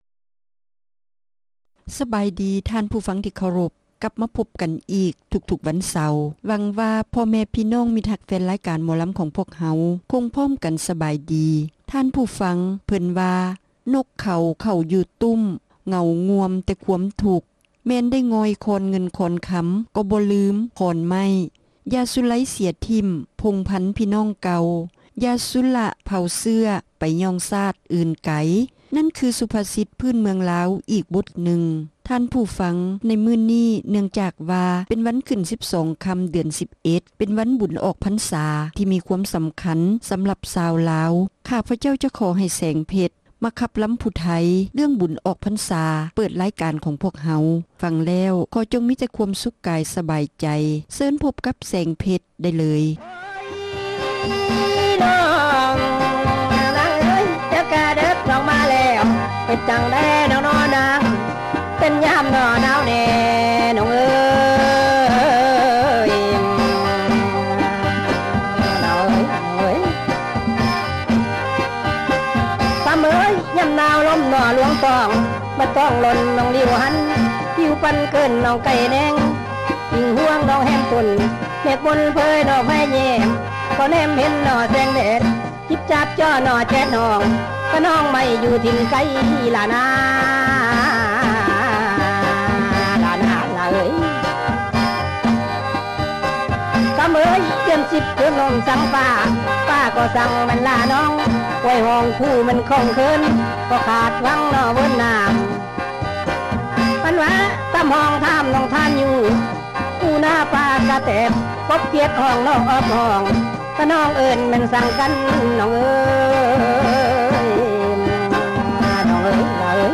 ຣາຍການໜໍລຳ ປະຈຳສັປະດາ ວັນທີ 26 ເດືອນ ຕຸລາ ປີ 2007